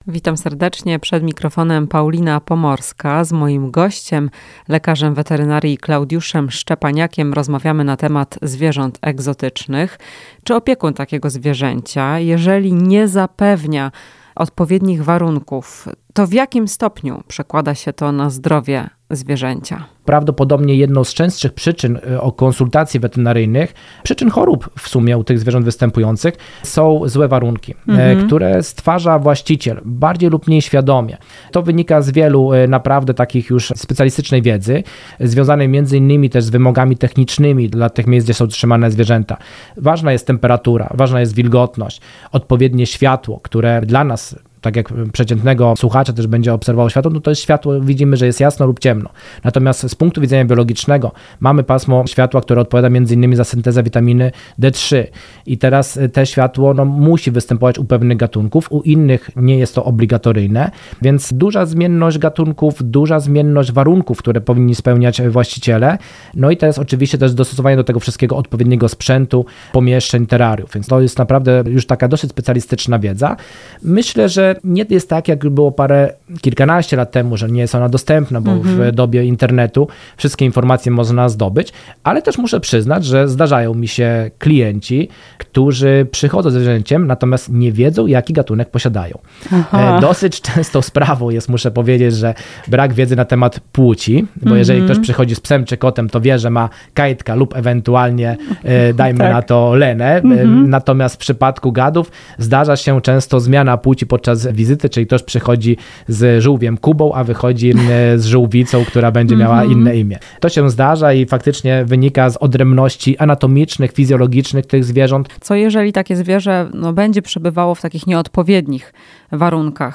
W "Chwili dla pupila" powiemy o warunkach, jakie muszą być zapewnione, by zwierzęta egzotyczne były zdrowe. Rozmowa z lek. wet.